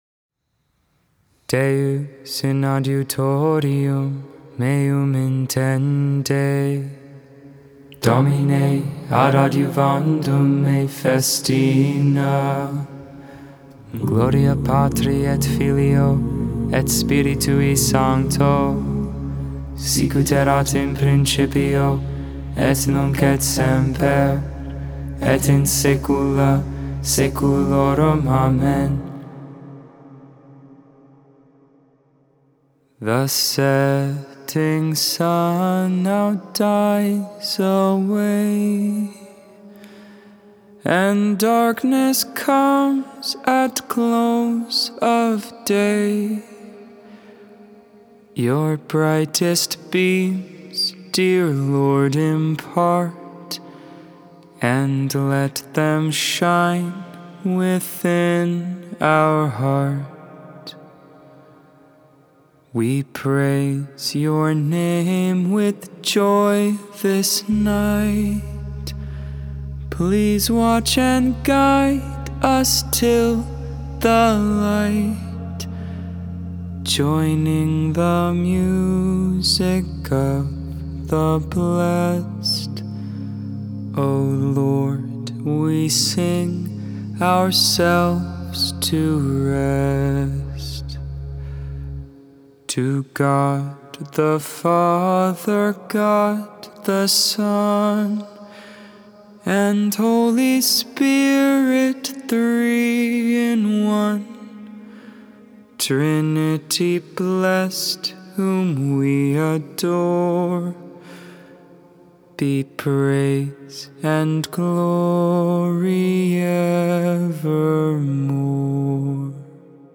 Vespers, Evening Prayer for the 5th Tuesday in Lent, April 5th, 2022.